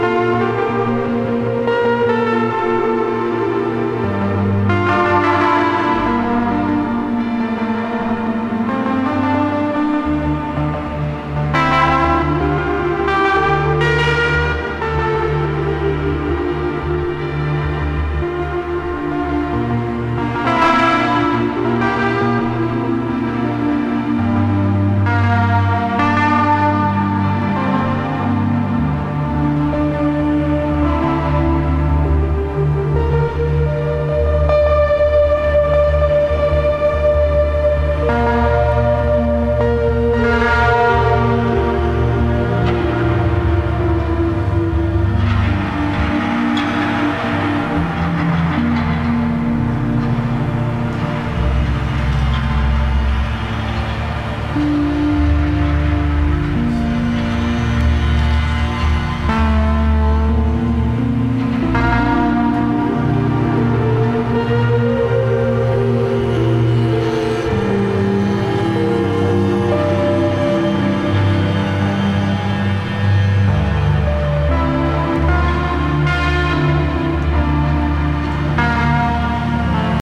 encontramos ainda assim música ambiente forte, emotiva